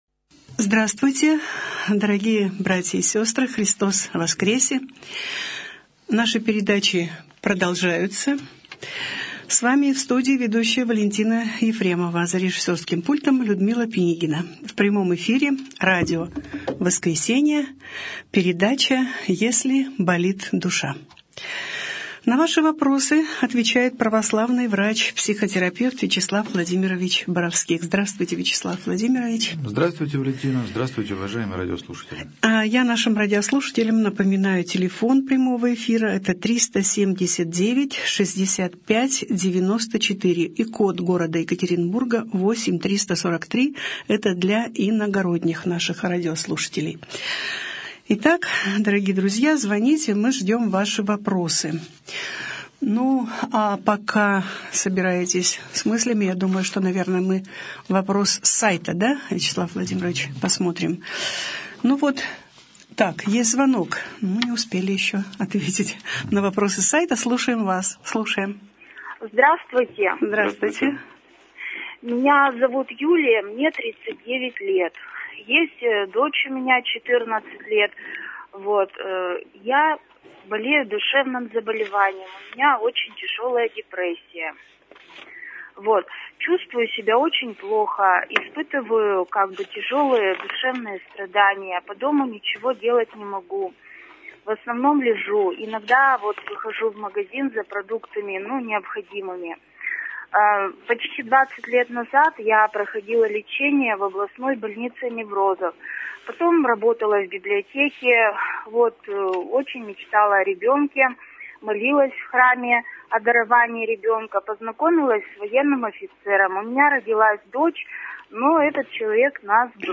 О душевном здоровье. Беседа с психологом | Православное радио «Воскресение»